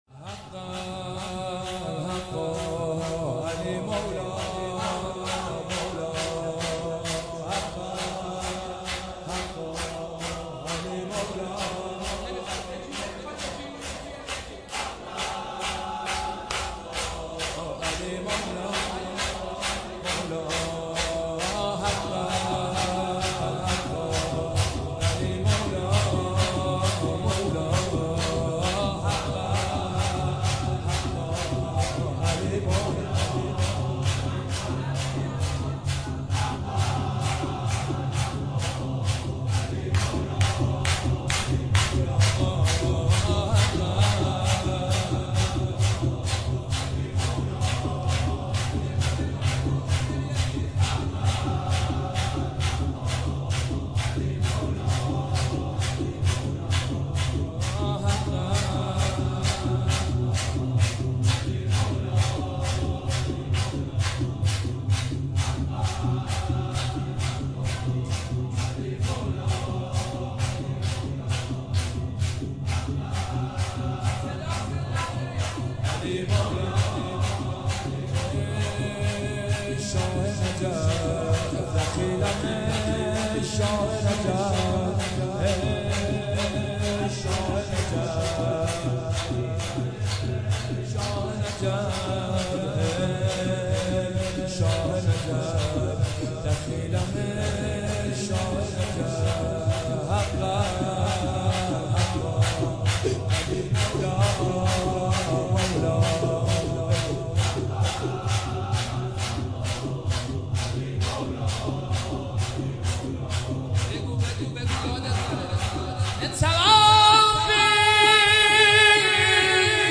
مداحی جدید
شب دوم محرم97 هیئت روضة‌ العباس